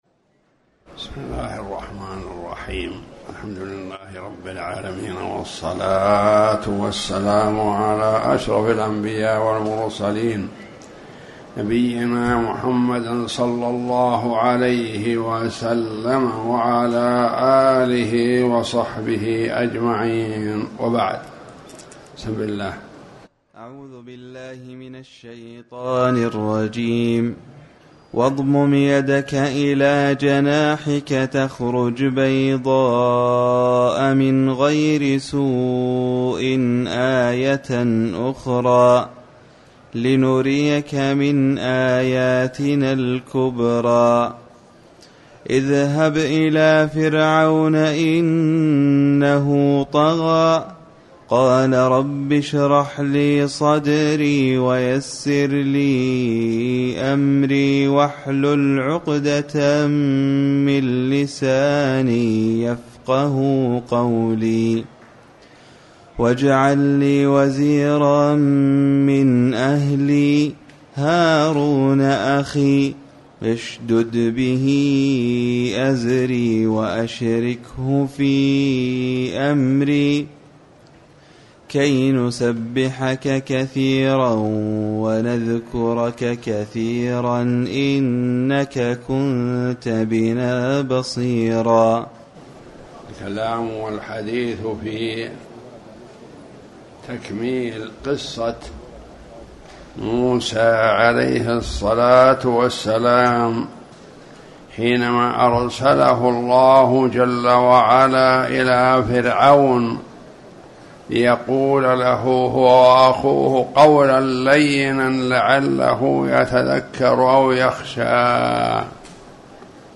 تاريخ النشر ٢٩ محرم ١٤٤٠ هـ المكان: المسجد الحرام الشيخ